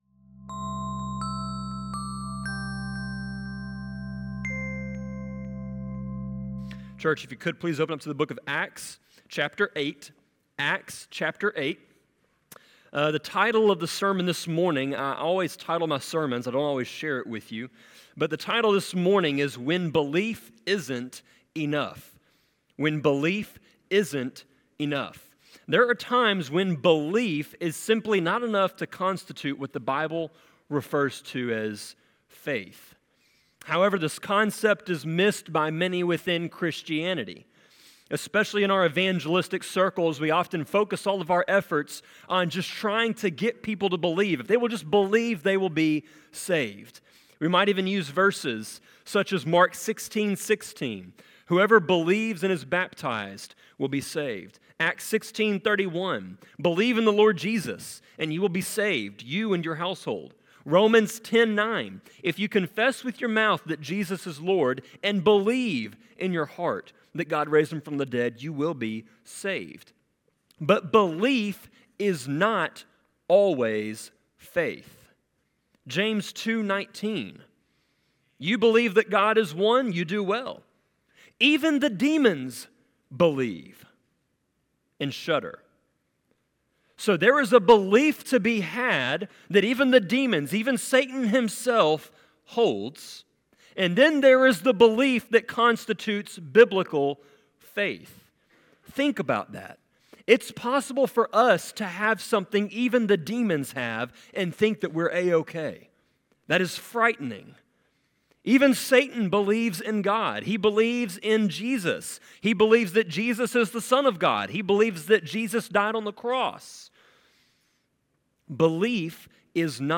Sermon-24.4.7.m4a